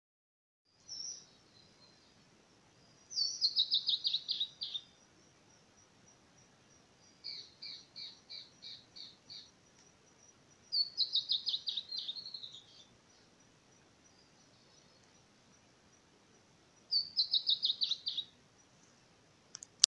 Tiluchi Colorado (Drymophila rubricollis)
Nombre en inglés: Bertoni´s Antbird
Localidad o área protegida: Parque Provincial Cruce Caballero
Condición: Silvestre
Certeza: Observada, Vocalización Grabada